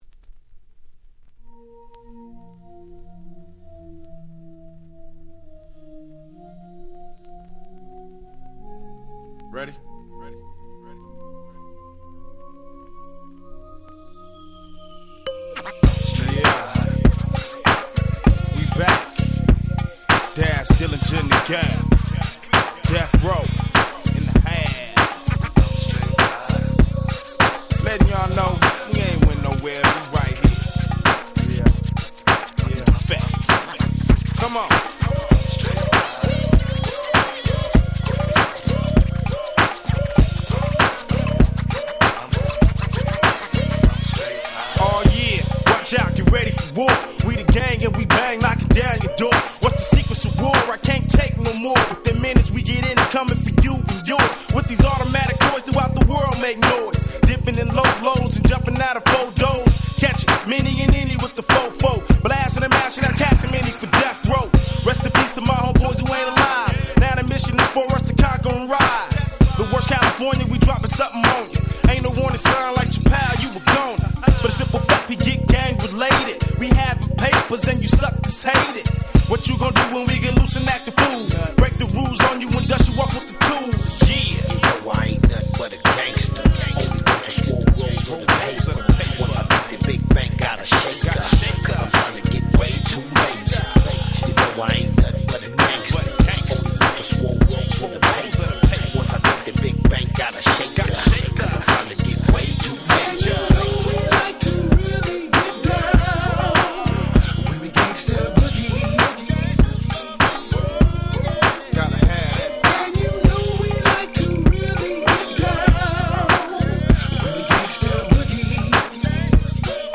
97' Very Nice West Coast Hip Hop !!
Gangsta Rap